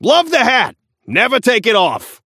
Shopkeeper voice line - Love the hat! Never take it off!
Shopkeeper_hotdog_t4_seasonal_02_alt_01.mp3